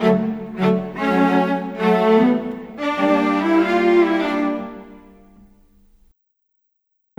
Rock-Pop 10 Cello _ Viola 03.wav